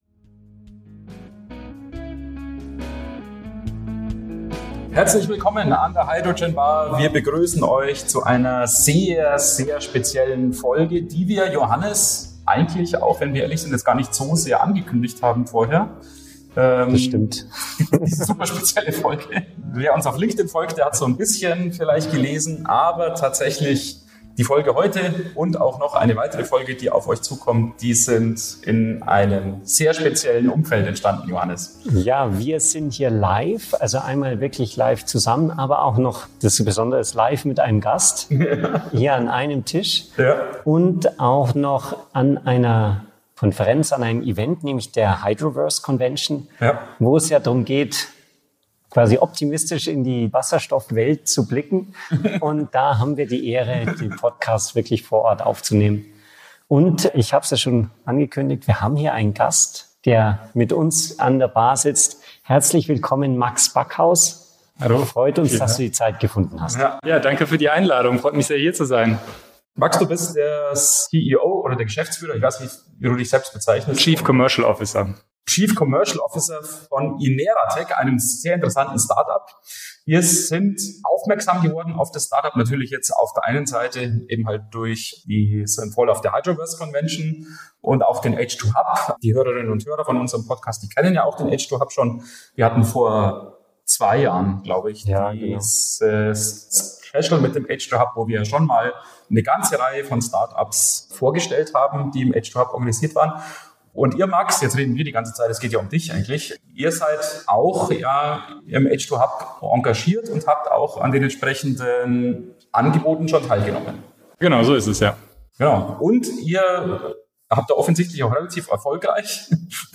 Willkommen zu einer neuen Folge an der Hydrogen Bar - aufgenommen im Rahmen der H2UB Hydroverse Convention 2025 in Essen.